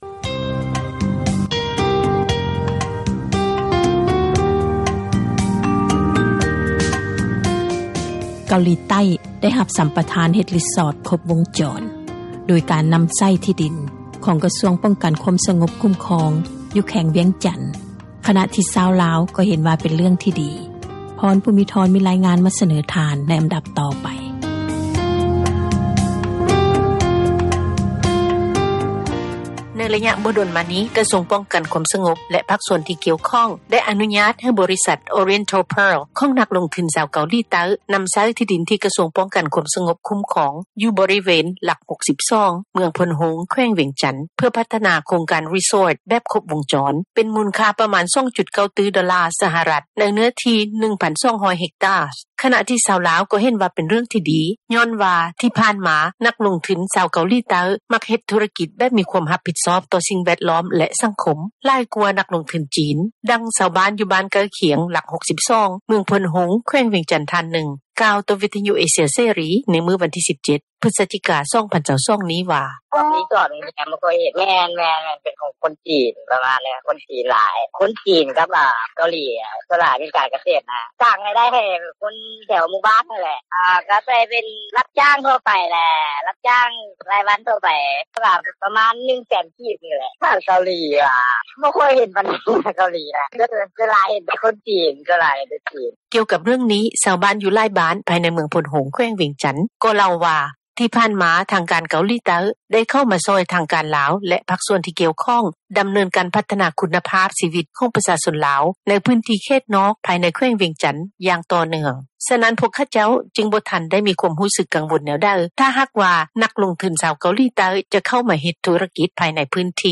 ດັ່ງຊາວບ້ານ ຢູ່ບ້ານໃກ້ຄຽງ ຫລັກ 62 ເມືອງໂພນໂຮງ ແຂວງວຽງຈັນ ທ່ານນຶ່ງ ກ່າວຕໍ່ວິທຍຸ ເອເຊັຽເສຣີ ໃນມື້ວັນທີ 17 ພຶສຈິກາ 2022 ນີ້ວ່າ: